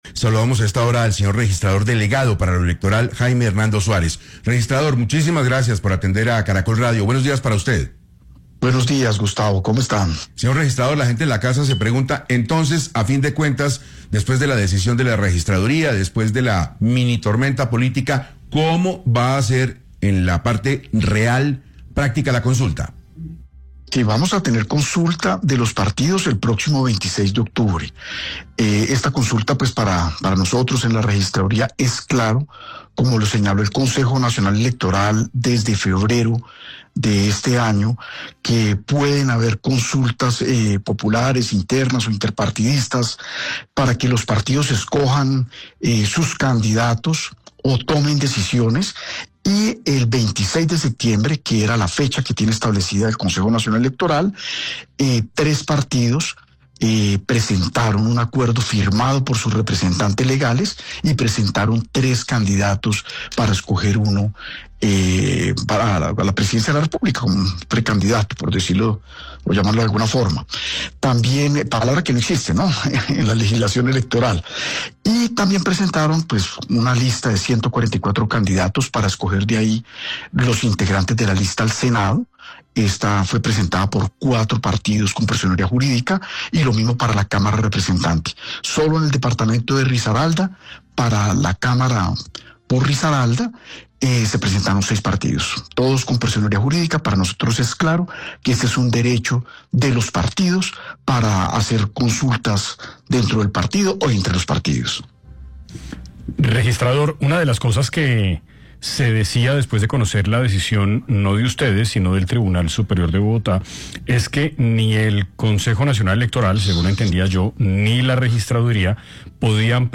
En entrevista con Caracol Radio, el registrador delegado para lo electoral, Jaime Hernando Suárez, confirmó que el próximo 26 de octubre se llevarán a cabo las consultas internas e interpartidistas de los partidos políticos, entre ellas la del Pacto Histórico, pese a las dudas generadas tras la decisión del Tribunal Superior de Bogotá.